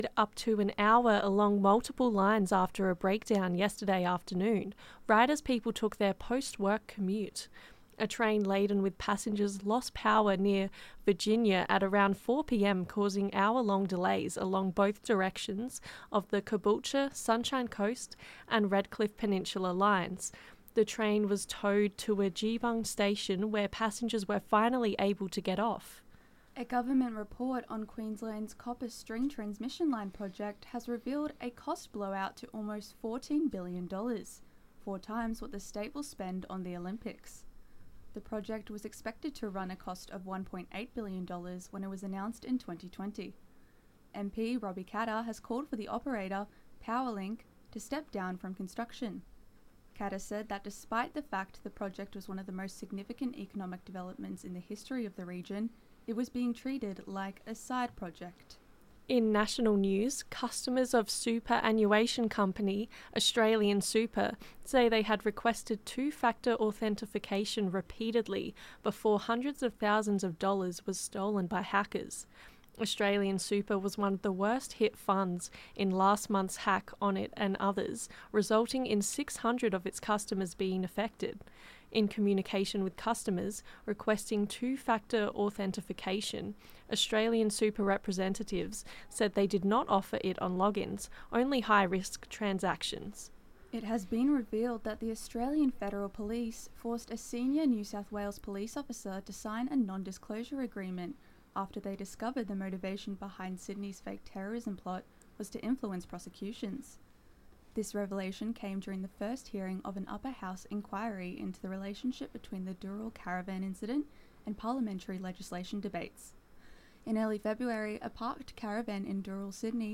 Zedlines Bulletin